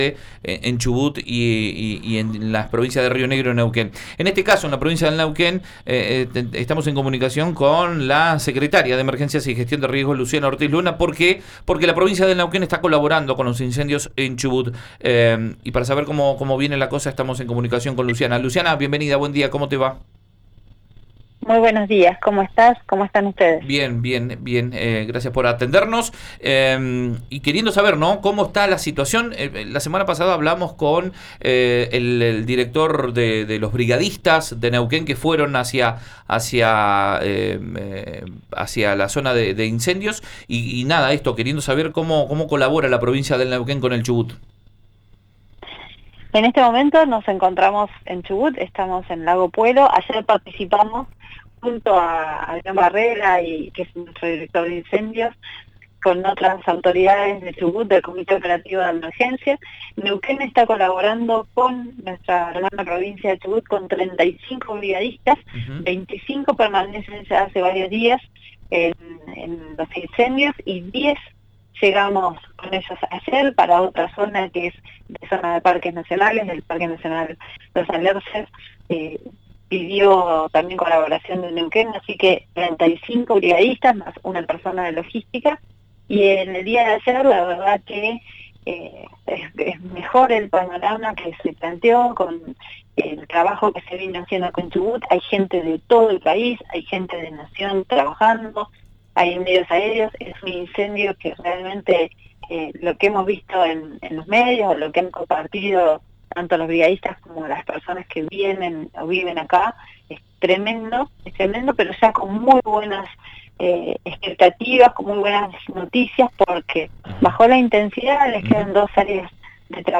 En diálogo con RÍO NEGRO RADIO, la secretaria en Emergencia y Gestión de Incendios de Neuquén, Luciana Ortiz, compartió detalles sobre los brigadistas presentes en Chubut para combatir los incendios en Lago Puelo.